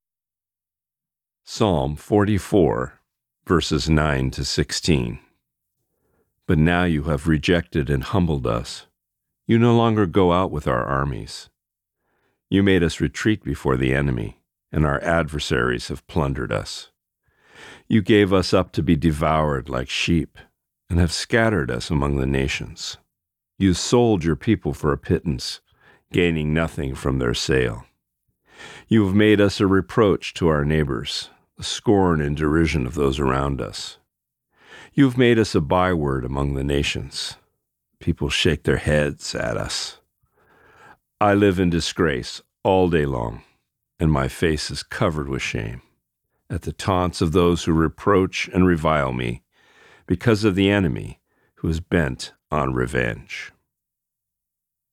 Reading:Psalm 44:9-16